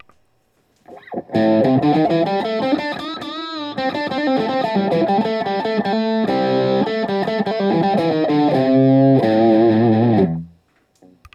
The bridge pickup delivers a fat lead tone that doesn’t have the bite of say, a Les Paul, but then that’s probably not the sound you’re chasing if you’re looking at a Guild S100.
All recordings in this section were recorded with an Olympus LS-10. Amp is an Axe-FX Ultra set to “Basic Brit 800” with no effects. Speaker is a QSC K12.
Guild-97-S100-BridgeRiff.wav